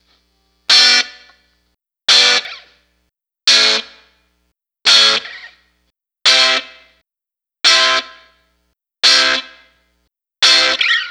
Track 02 - Guitar Stabs.wav